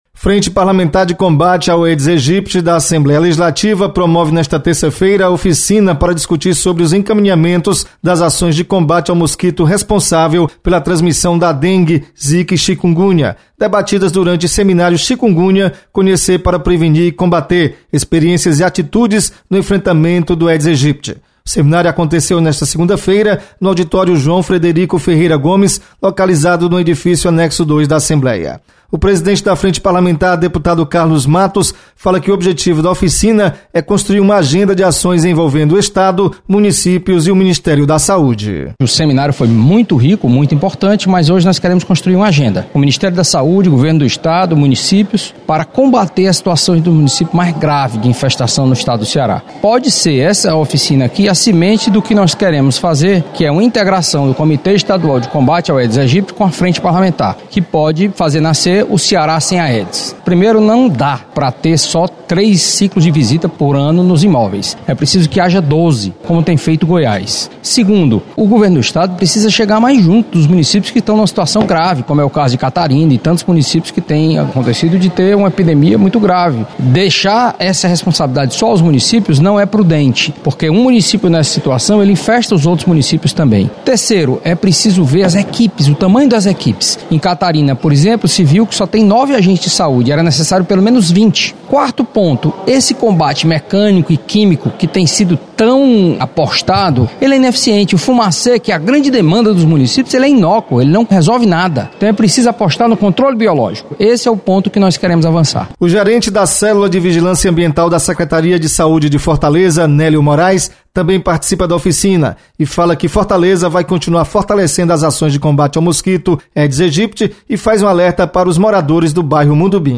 Frente Parlamentar de Combate ao Aedes aegypti promove oficina com autoridades da saúde pública. Repórter